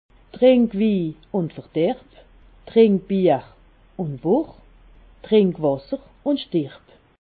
Expressions populaires
Haut Rhin
Ville Prononciation 68
Ribeauvillé